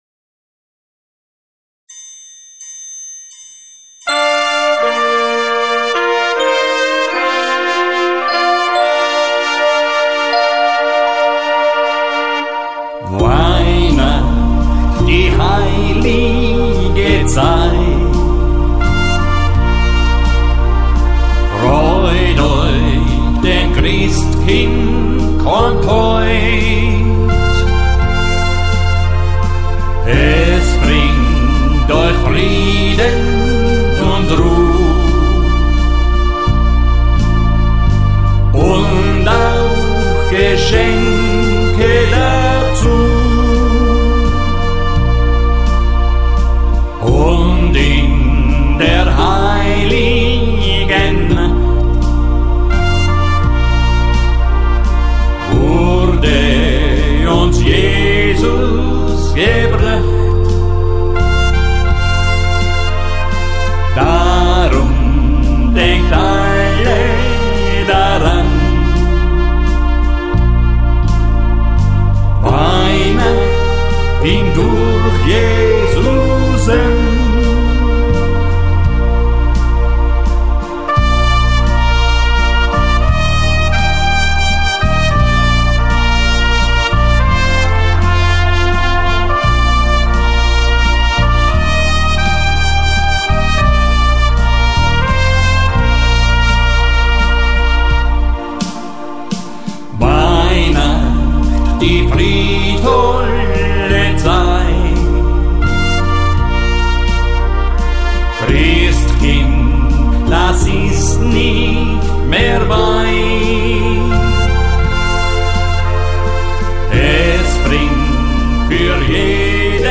Tanz- und Unterhaltungsmusik